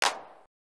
Clap (Transylvania).wav